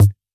Index of /musicradar/retro-drum-machine-samples/Drums Hits/WEM Copicat
RDM_Copicat_MT40-Kick02.wav